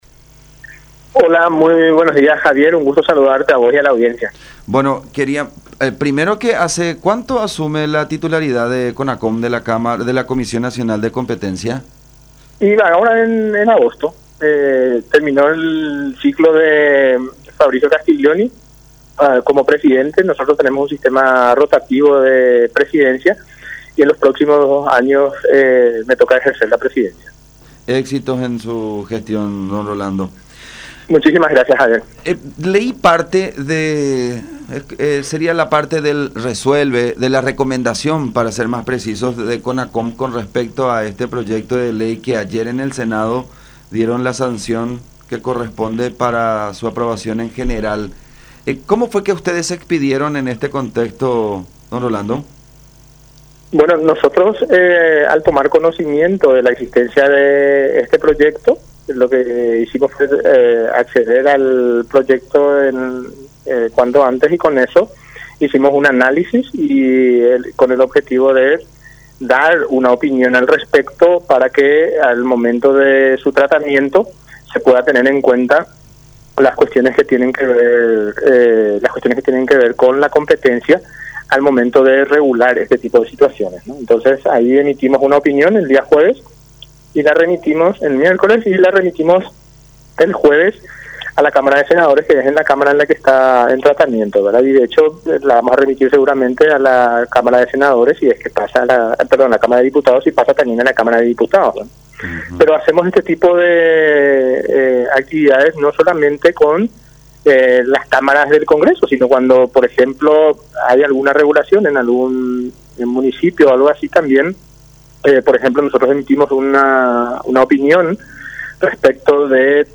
No existe desde el punto de vista técnico ni un análisis de mercado que respalde esa justificación”, aseveró Díaz en conversación con Todas Las Voces.